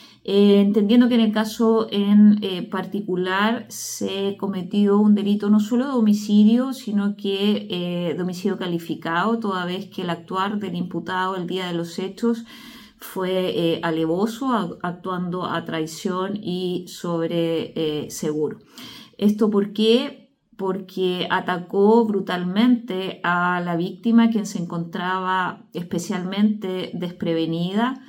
La información la confirmó la fiscal de Villarrica, Paola Varela.